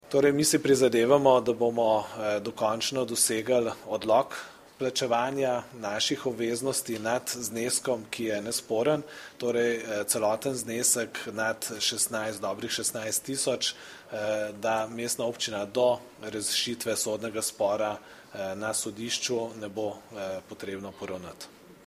Novo mesto, 20. januar 2015 - Župan Mestne občine Novo mesto Gregor Macedoni je na današnji dopoldanski novinarski konferenci predstavil delo Mestne občine Novo mesto v zadnjem mesecu ter izpostavil ključne korake, ki jih je mestna občina storila pri posameznih aktualnih projektih.